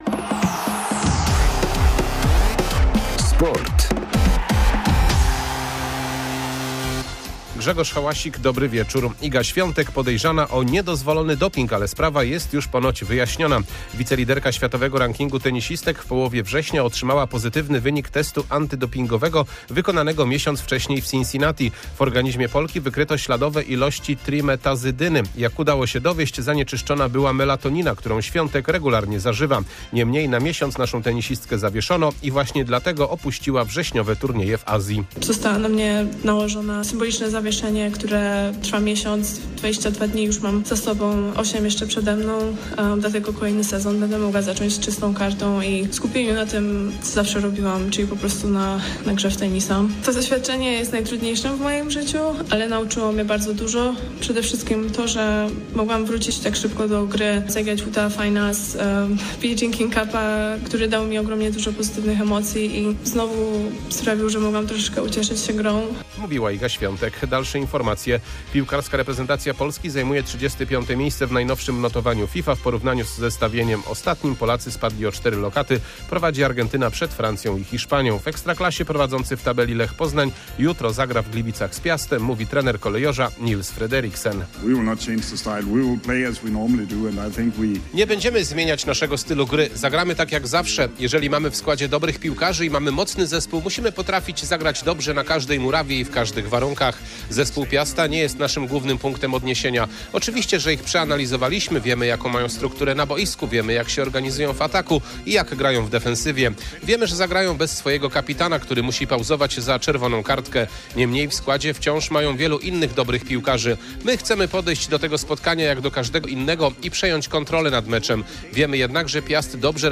28.11.2024 SERWIS SPORTOWY GODZ. 19:05